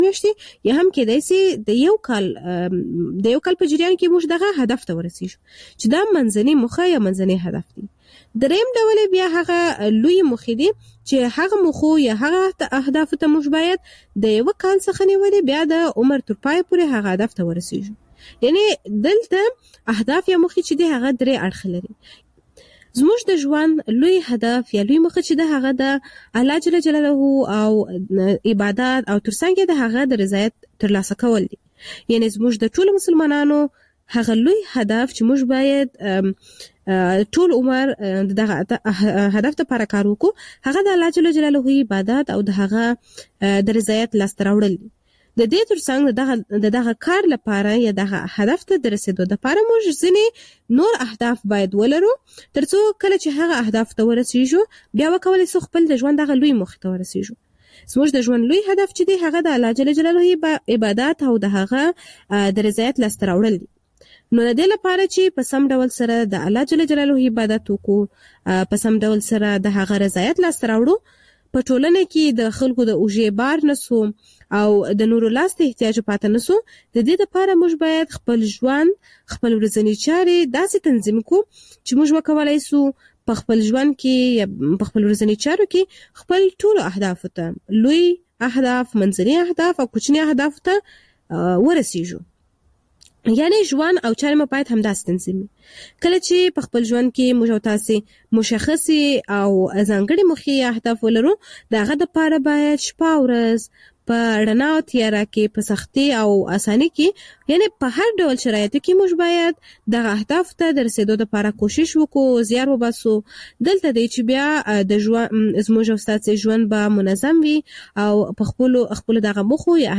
مجله خبری صبح‌گاهی
پخش زنده - رادیو آزادی